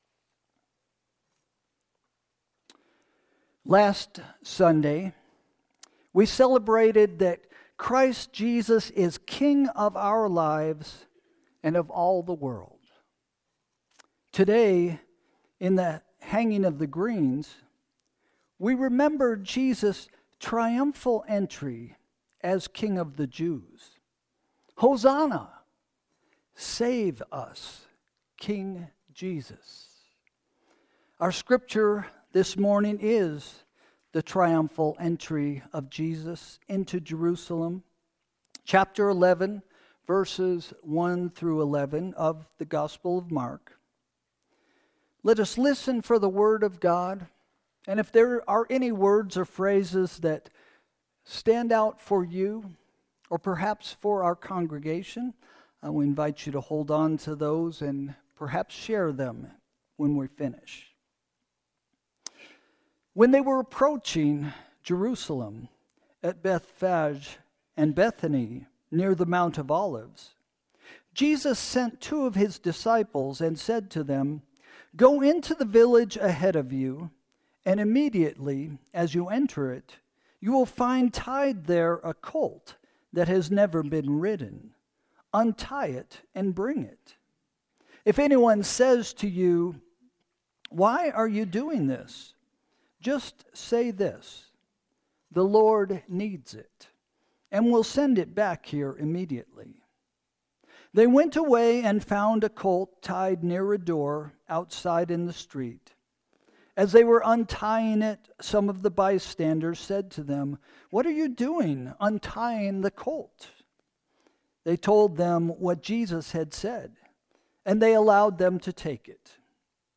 Sermon – November 30, 2025 – “What Do You Expect?”